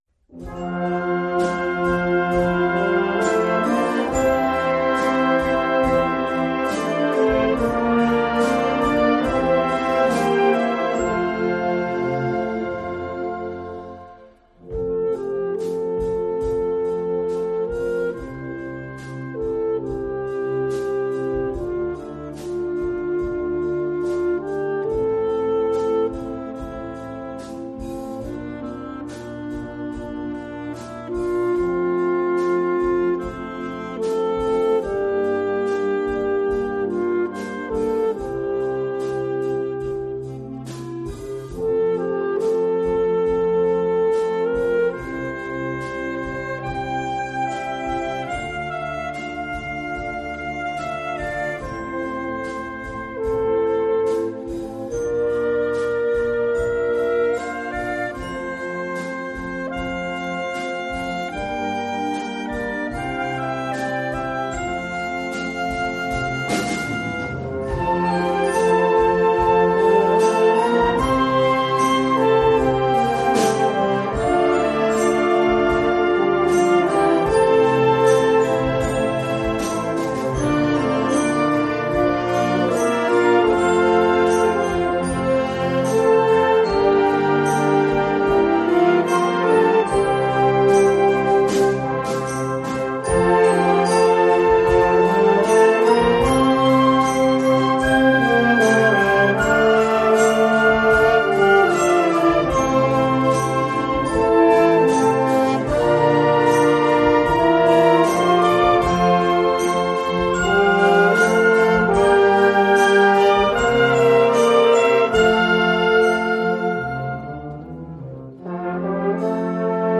Œuvre pour saxophone alto solo
et orchestre d’harmonie.